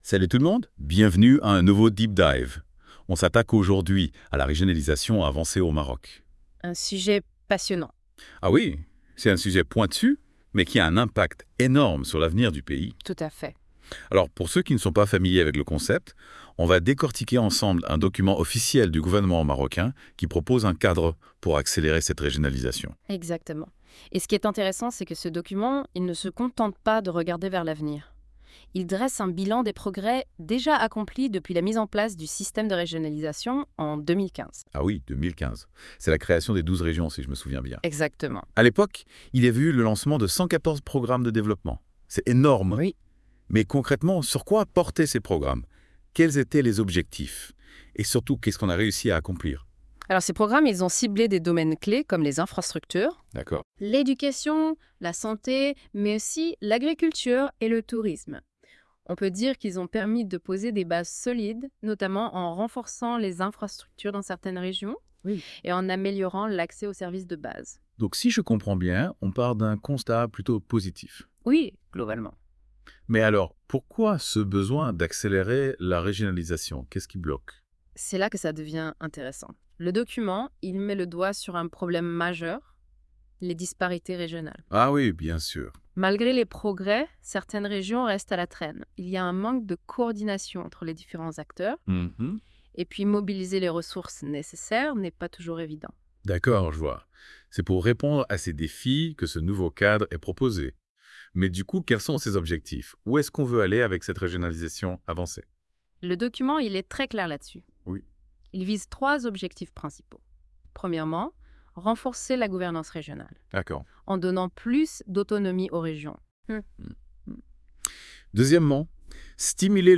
+ Débat - Podcast : les chroniqueurs de la Web Radio débattent des idées contenues dans cet article à travers ces questions :